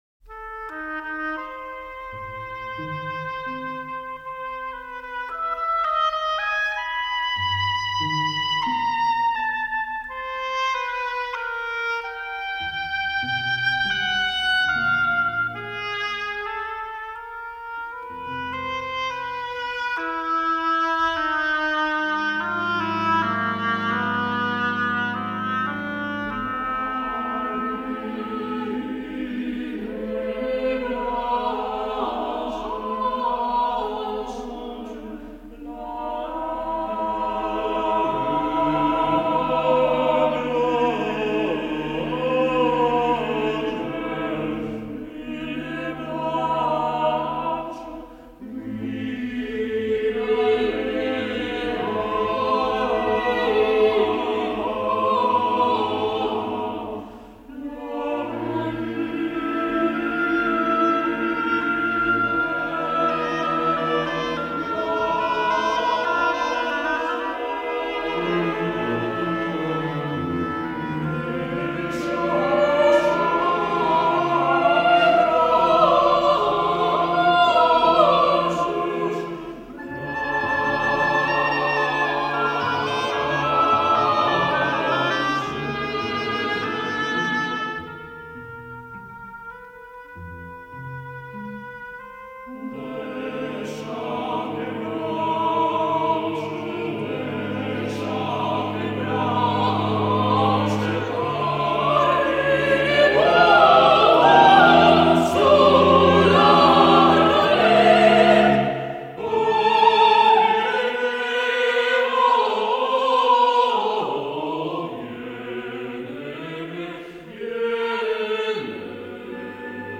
Vokālā mūzika